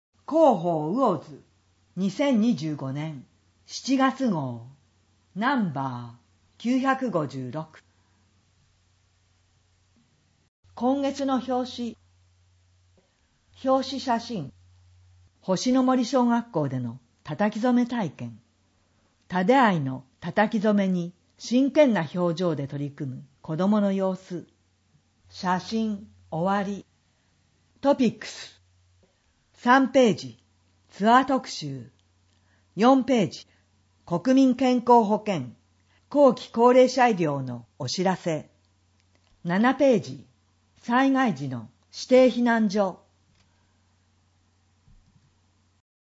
声の広報
魚津市では、音訳サークルうぐいすの会にご協力いただき、視覚障害の方を対象に「広報うおづ」の音訳CDを無料で発送しています。